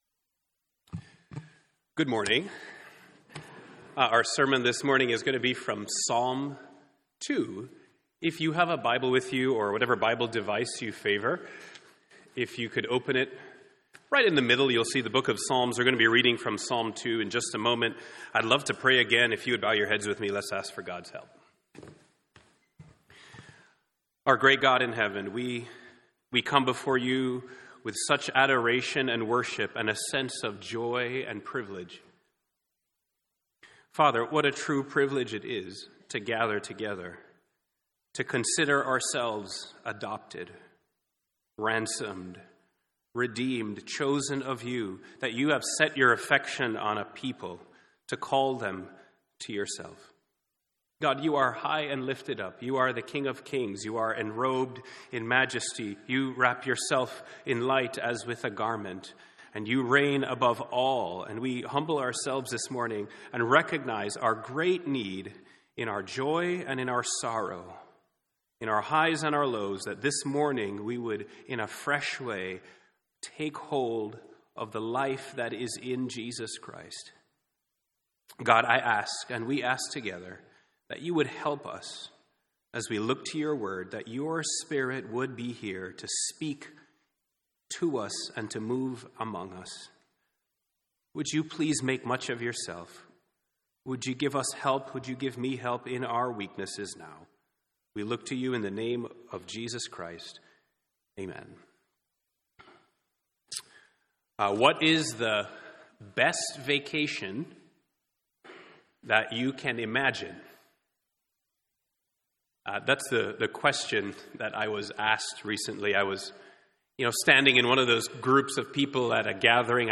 Sermons on Psalm 2 — Audio Sermons — Brick Lane Community Church